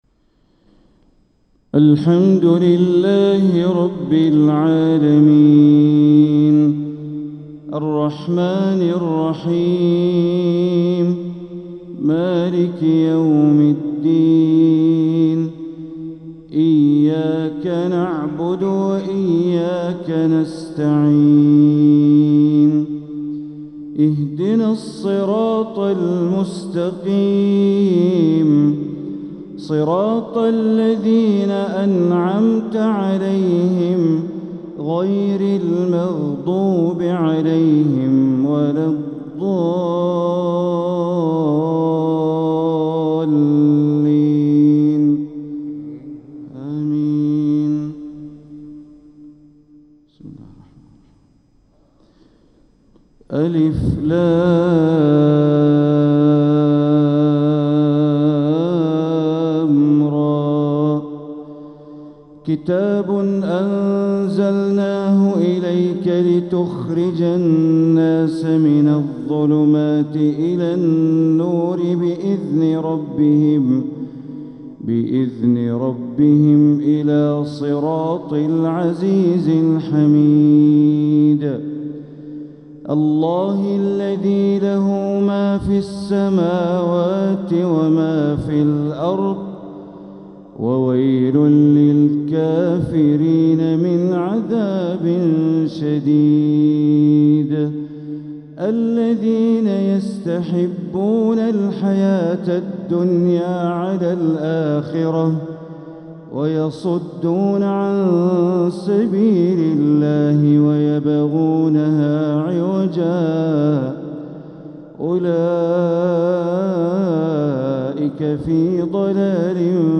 تلاوة من سورة إبراهيم فجر الأحد ١٥ ربيع الأول ١٤٤٧ > 1447هـ > الفروض - تلاوات بندر بليلة